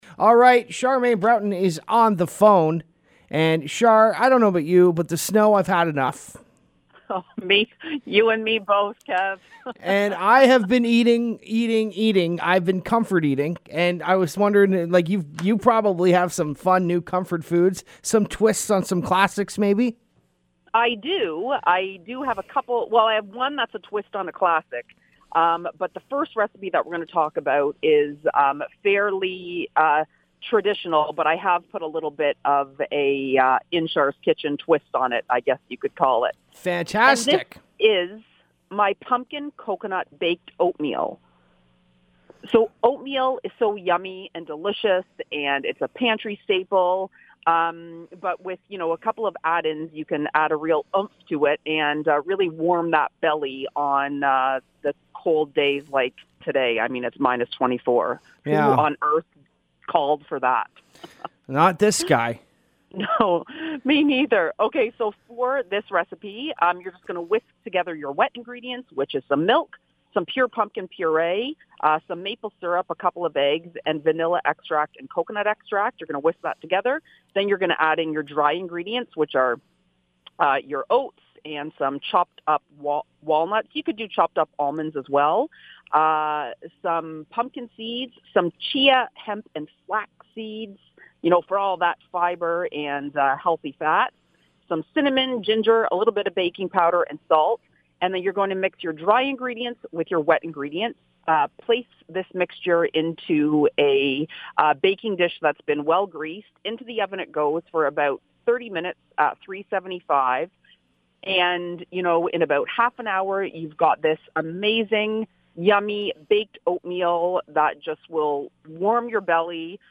Here is the interview!